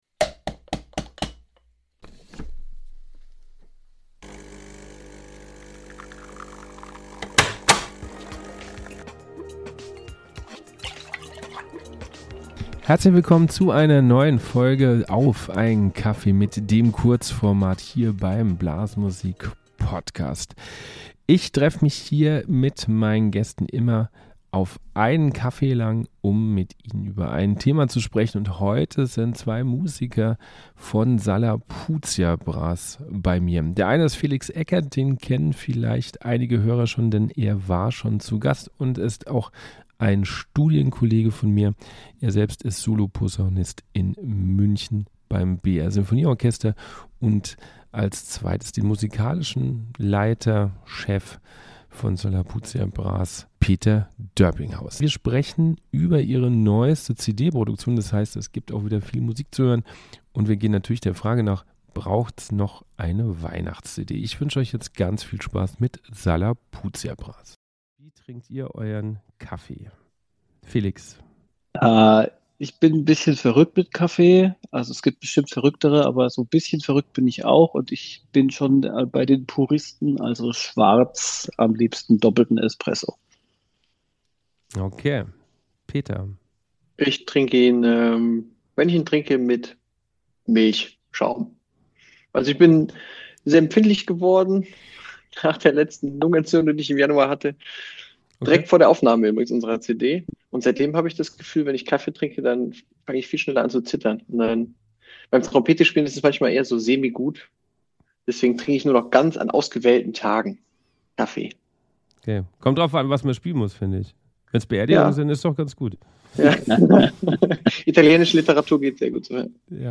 Es gibt viel Musik und Einblicke in die Produktion.